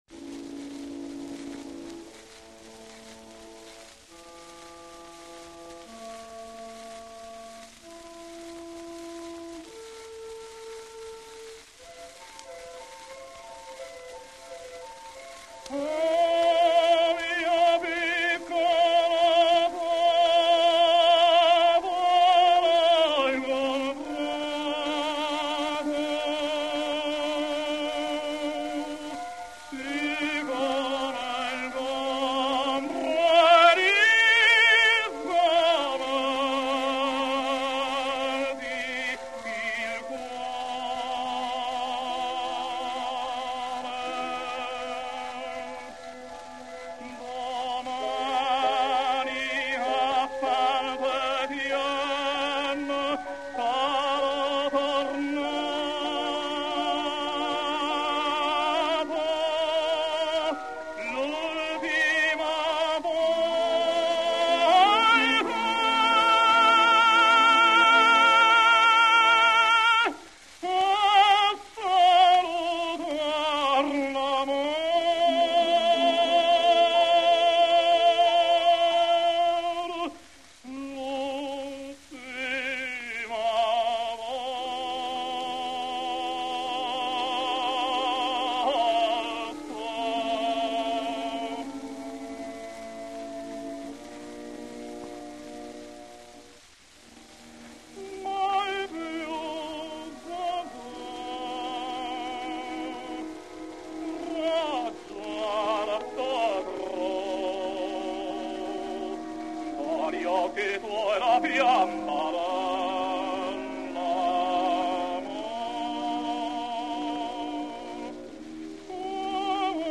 Giovanni Martinelli [Tenor]